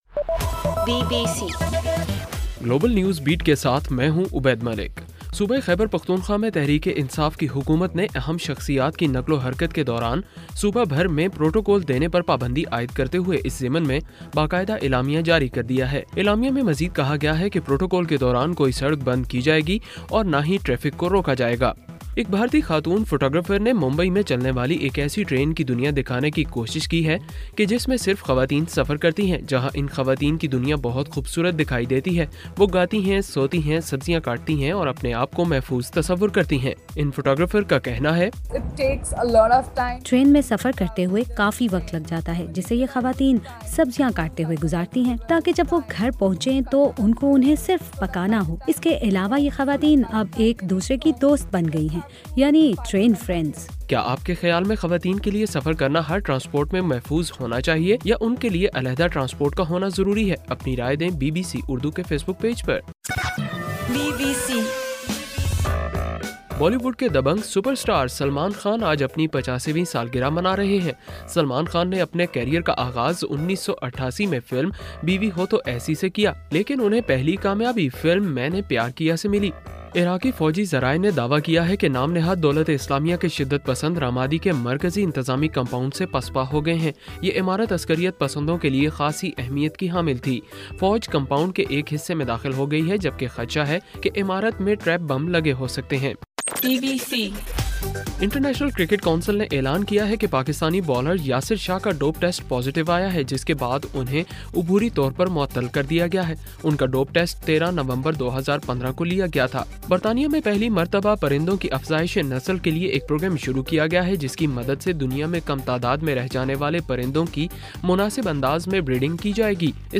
دسمبر 27: رات 9 بجے کا گلوبل نیوز بیٹ بُلیٹن